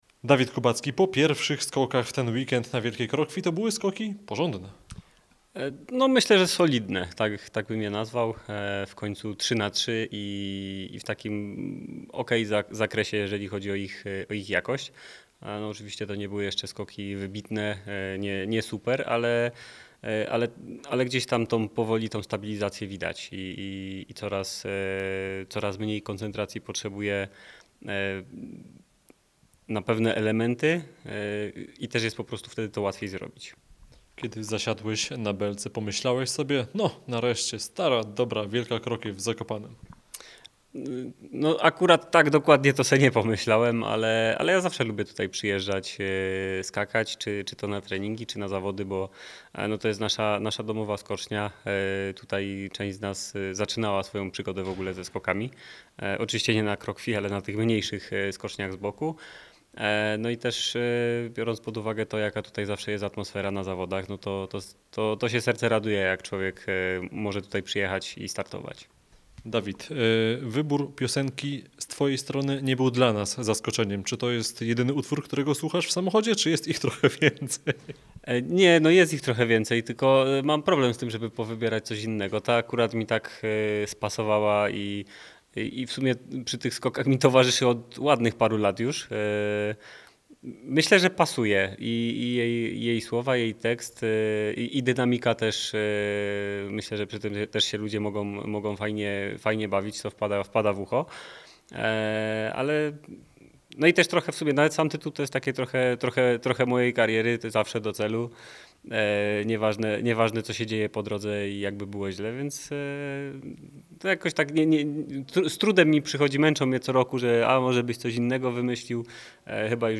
Dawid-Kubacki-po-kwalifikacjach-w-Zakopanem.mp3